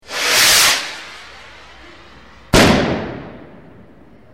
FIREWORKS FIREWORK
Ambient sound effects
Descargar EFECTO DE SONIDO DE AMBIENTE FIREWORKS FIREWORK - Tono móvil
fireworks_firework.mp3